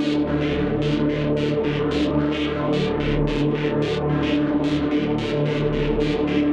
Index of /musicradar/dystopian-drone-samples/Tempo Loops/110bpm
DD_TempoDroneC_110-D.wav